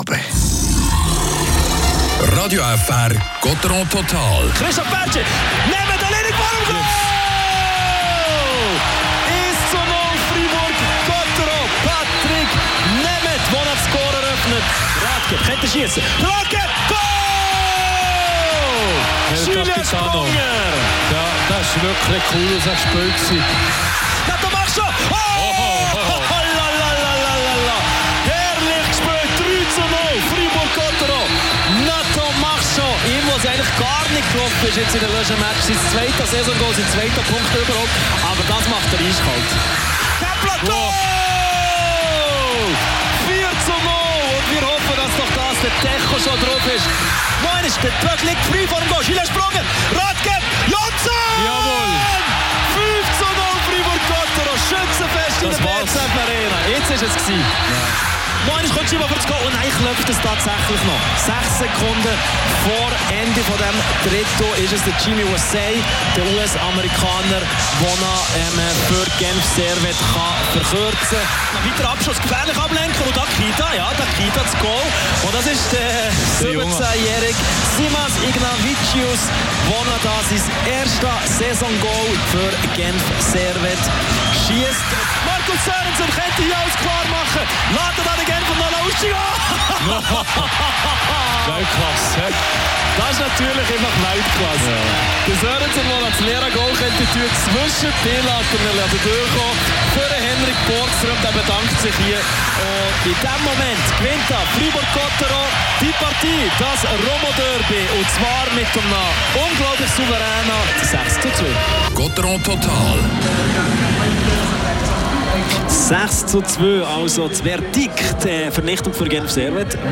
Spielanalyse
Interview mit Christoph Bertschy.